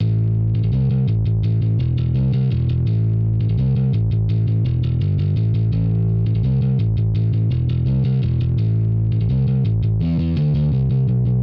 続いて、モジュレーションありです。
モジュレーションがある音源の方が、若干歯切れが良くなっていますね。
11_Bass_mod.mp3